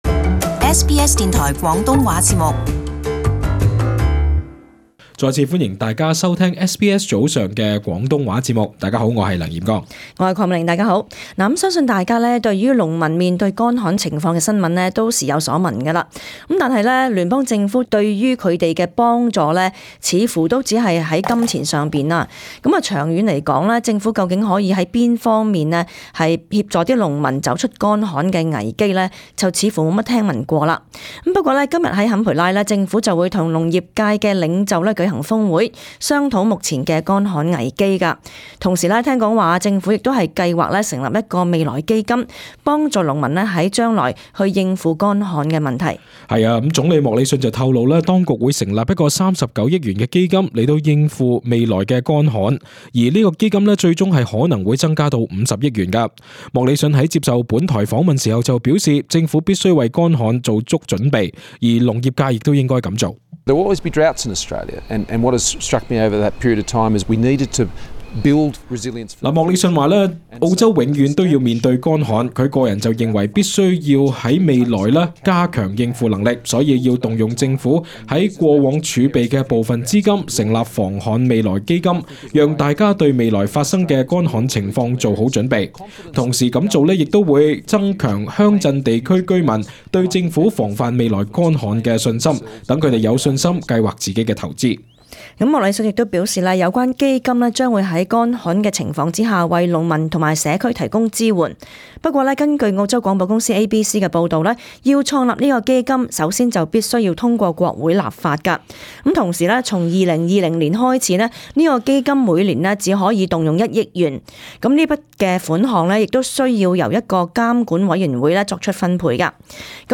【時事報導】未來基金助農民應對乾旱